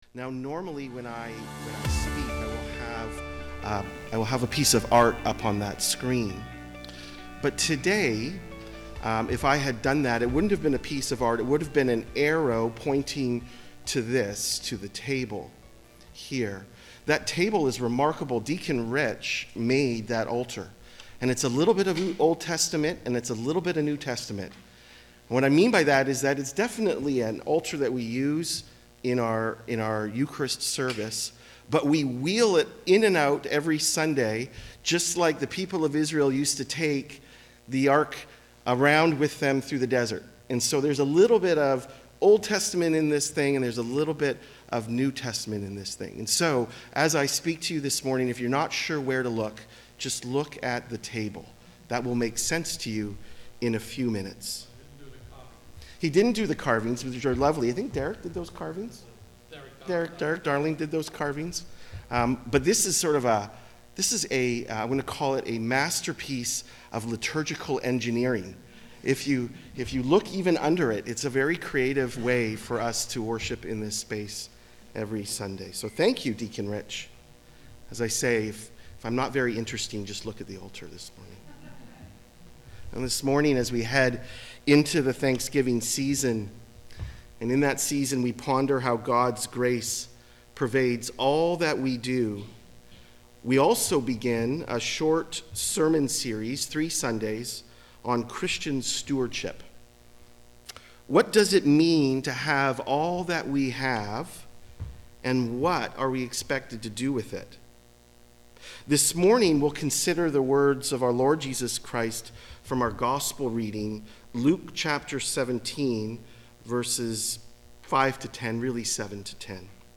Sermons | Church of the Ascension